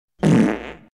Fart Reverb Sound Effect - Botão de Efeito Sonoro